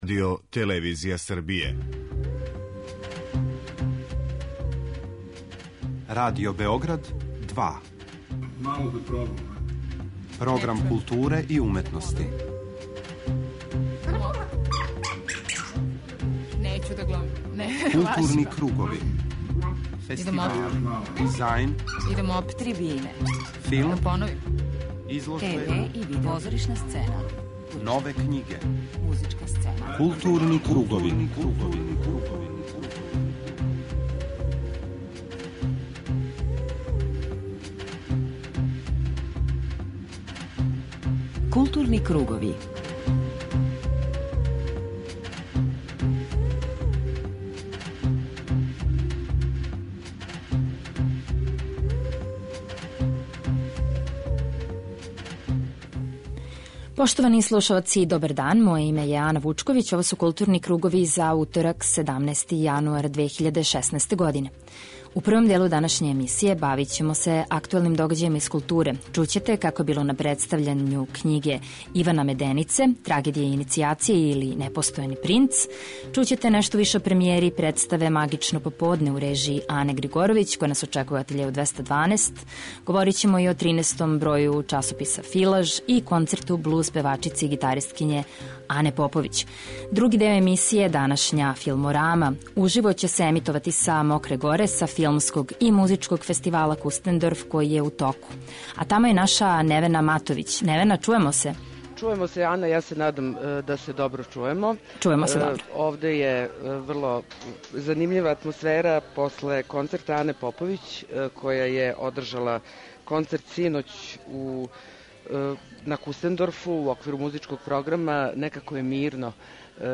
Данашња Филморама, која се емитује из Капор бара на Мећавнику, угостиће селекторе, чланове жирија, као и ауторе филмова из који ће бити приказани у оквиру Такмичарског програма.